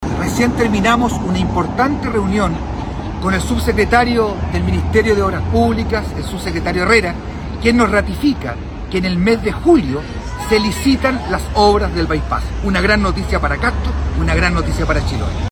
ALCALDE-VERA-SOBRE-BY-PASS.mp3